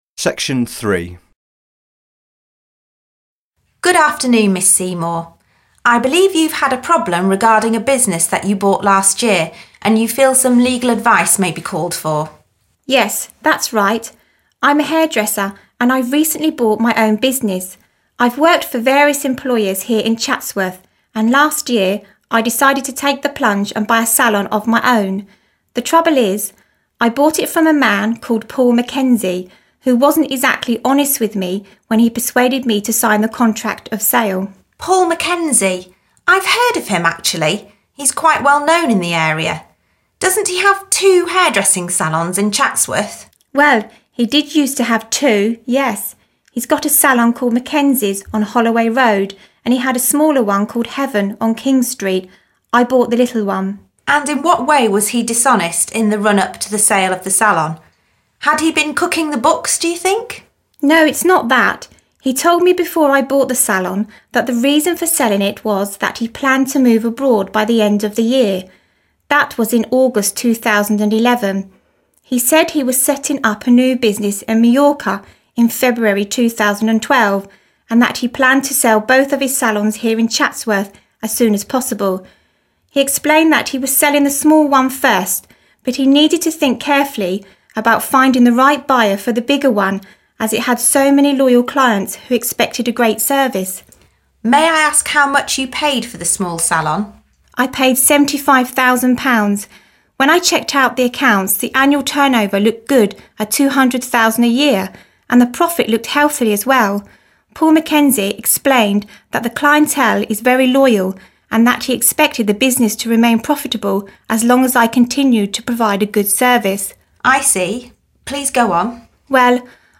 Listening Activity